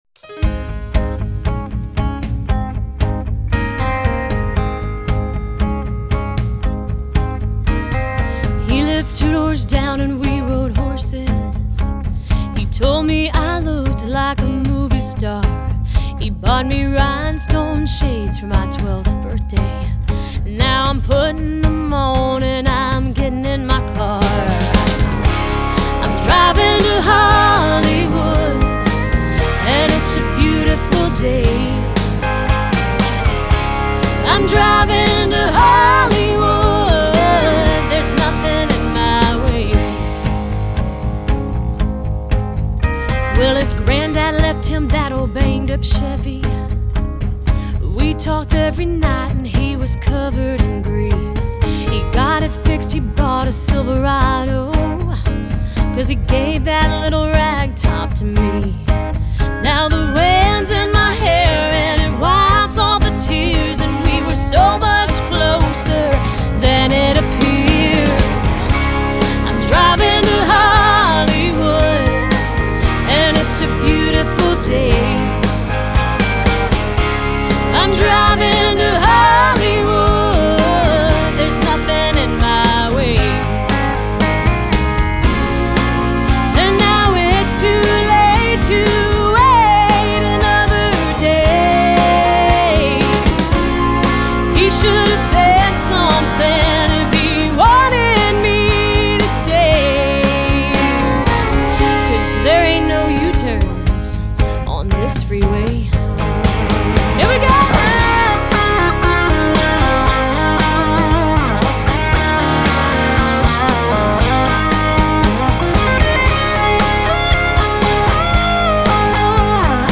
MASTER RECORDINGS - Country